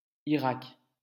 k corps, avec, quand,
Irak sky